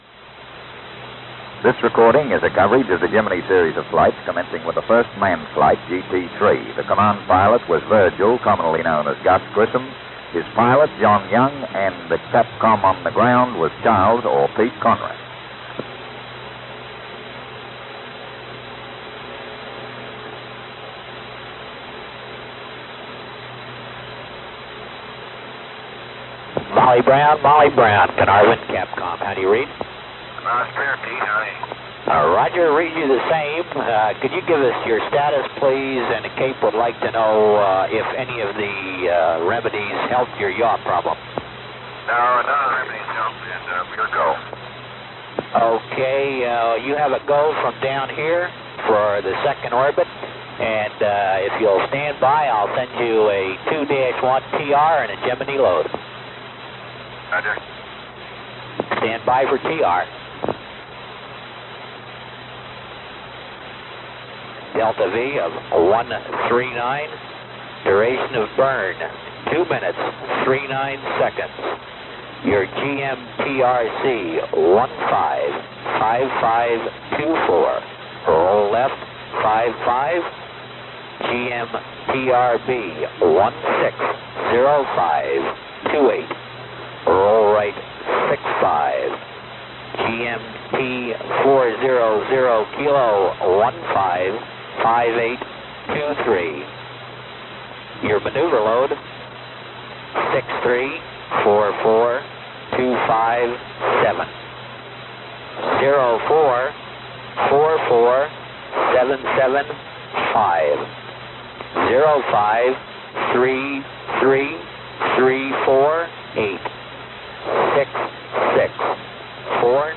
At the end of the Gemini series, a compilation tape of Carnarvon clips from all the manned missions (with the exception of GT10), was produced.